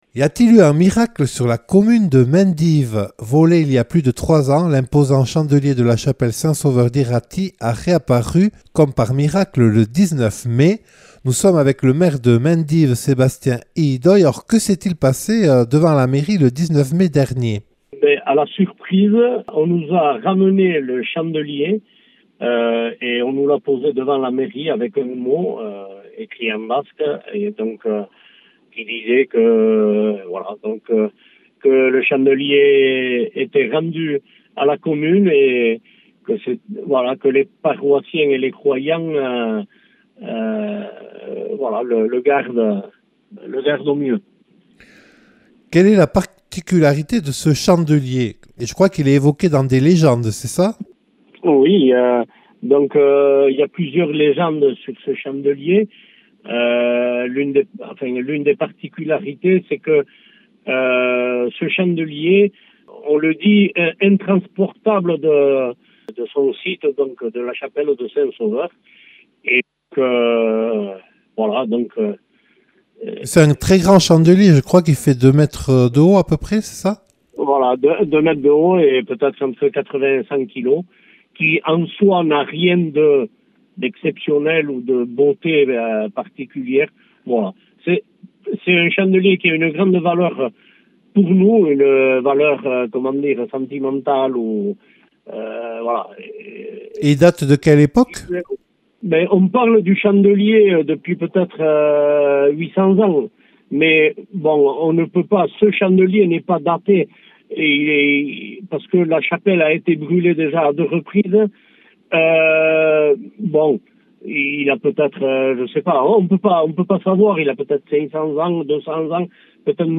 Volé il y a plus de trois ans, l’imposant chandelier de la chapelle Saint-Sauveur d’Iraty a réapparu le 19 mai. Les réponses de Sébastien Ihidoy, maire de Mendive.